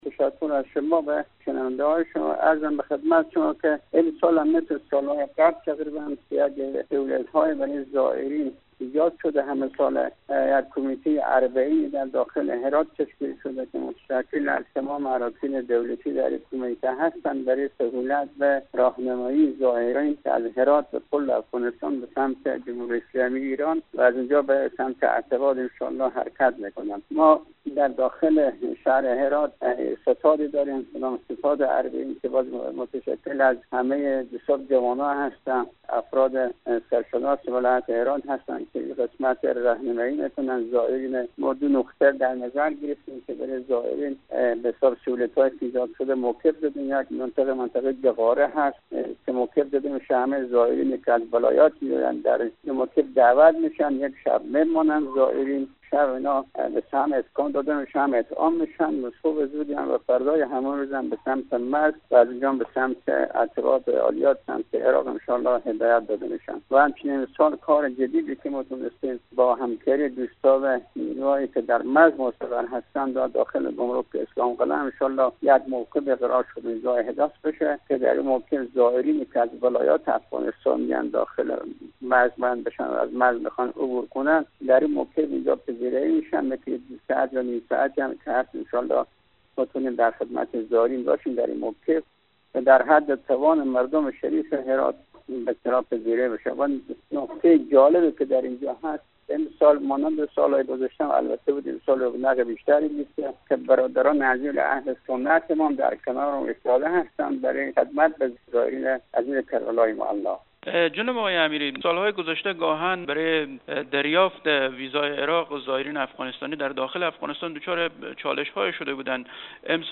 در گفت و گو با برنامه انعکاس رادیو دری